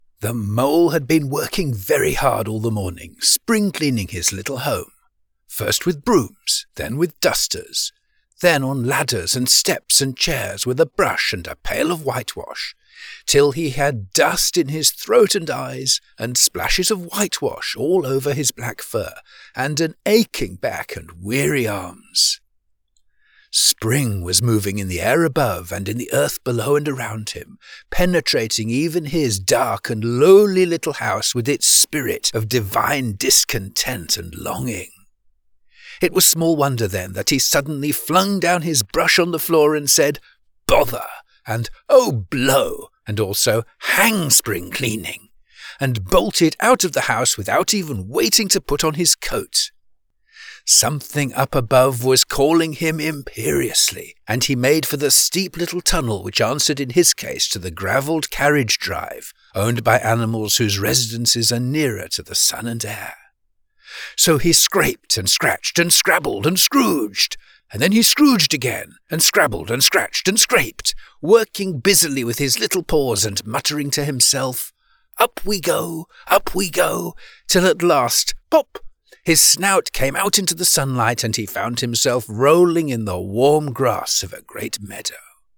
British Children's Book Narrator: